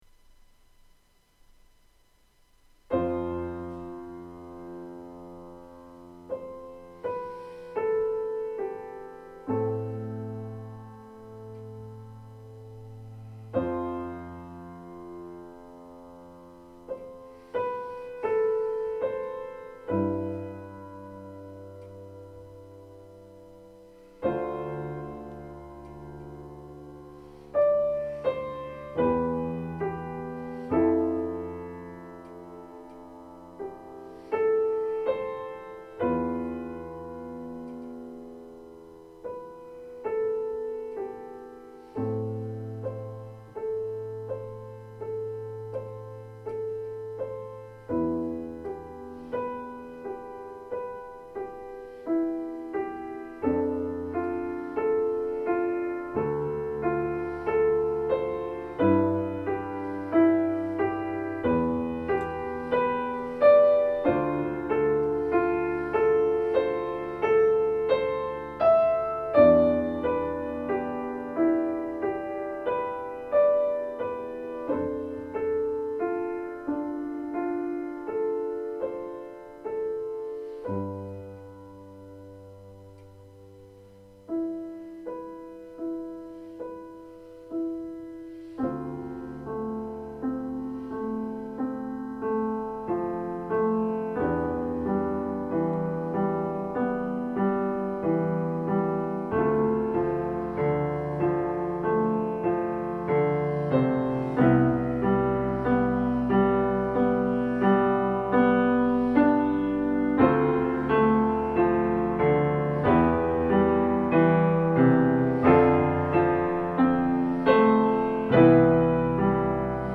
Geïnspireerd door de subtiliteiten van de oude kerktoonsoorten en -muziek ben ik nu bezig om sferen en verhalen in deels verstillende lijnen vorm te geven in aandachtsvolle composities.
Deze ervaringen hebben me geïnspireerd om dit meerdelige werk te schrijven, het zijn sfeerbeelden in meditterane sfeer.